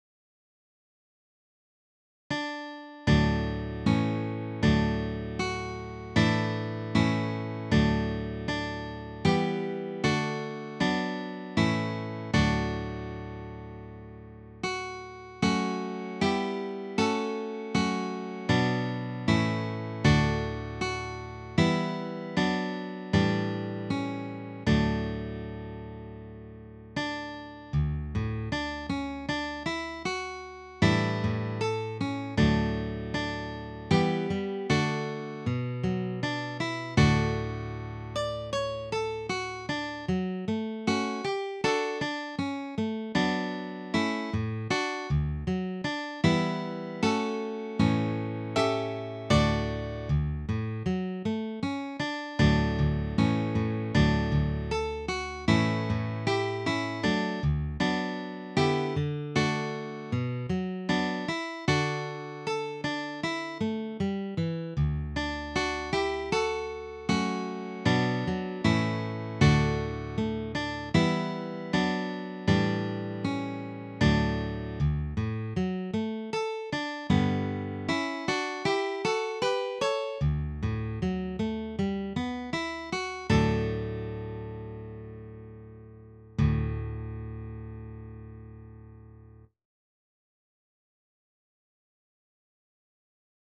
DIGITAL SHEET MUSIC - FLATPICK/PLECTRUM GUITAR SOLO
Sacred Music, Preludes, Graduals, and Offertories
Dropped D tuning